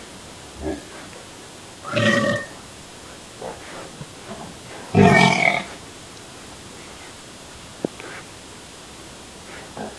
Странные животные звуки, похоже на свиней